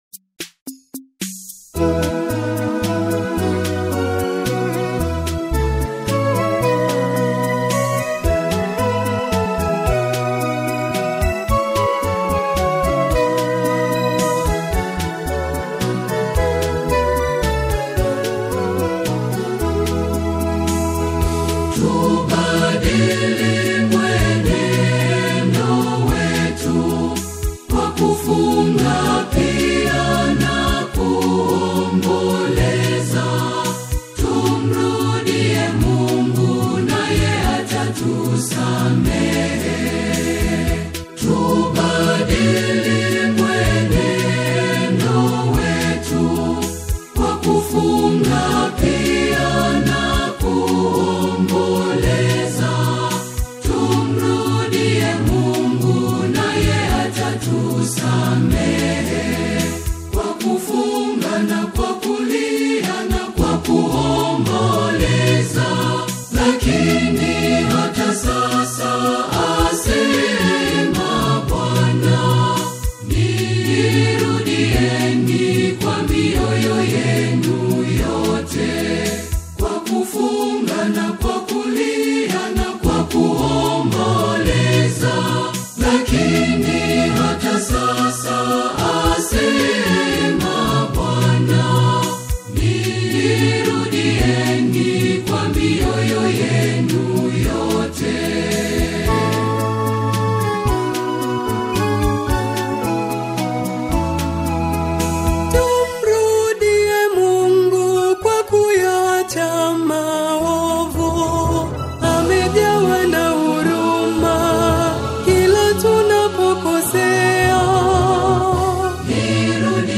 East African choral music